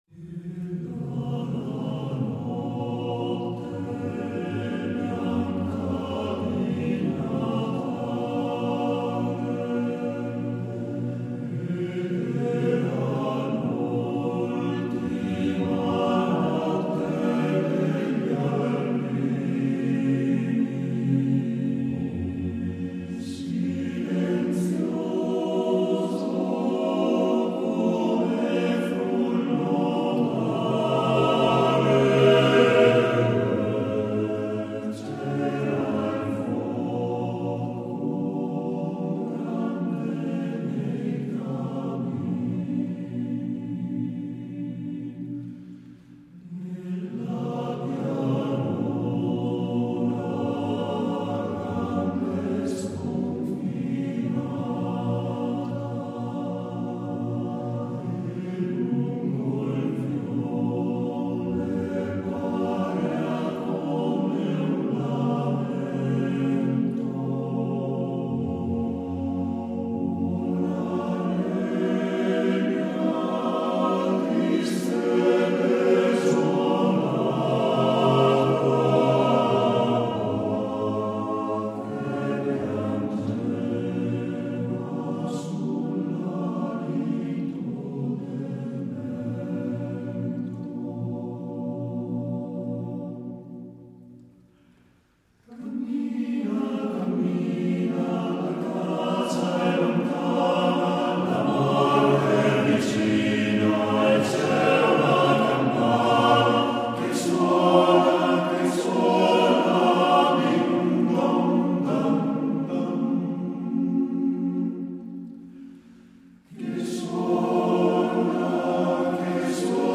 REPERTORIO DEL CORO ANA AVIANO